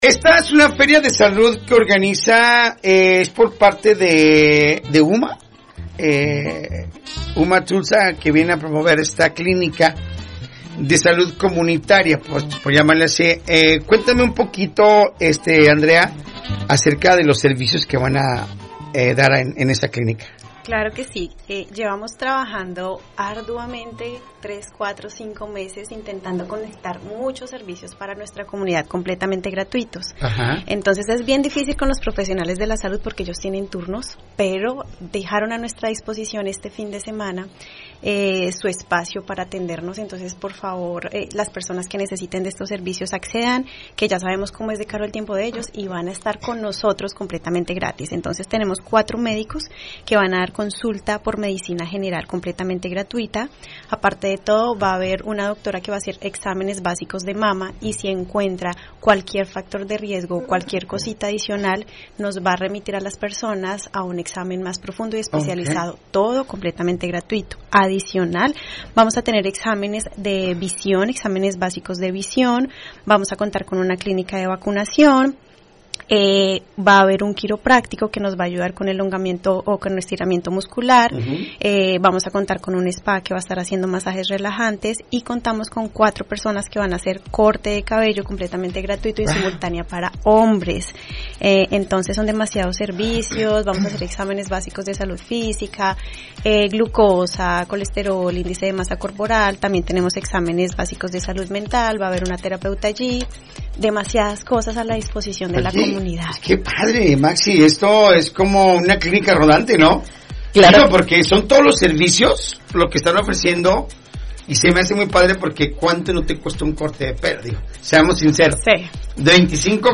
Entrevista-UMATulsa-20Octubre25.mp3